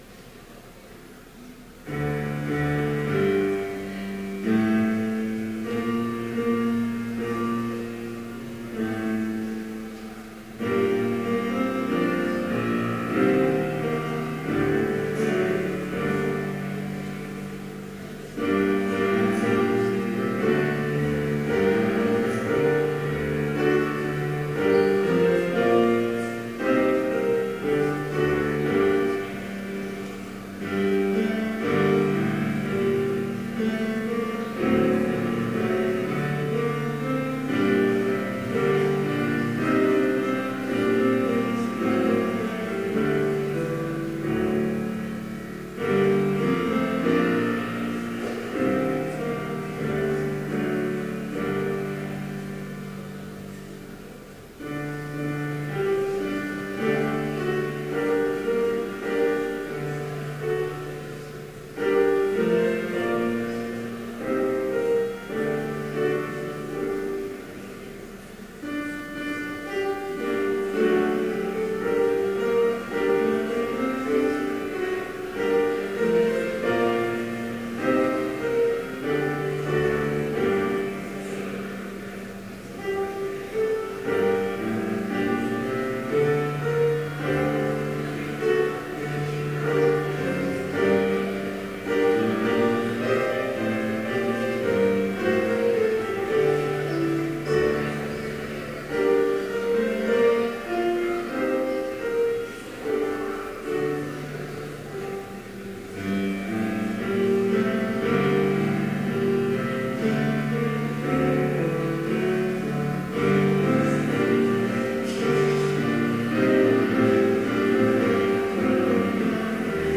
Complete service audio for Chapel - September 30, 2014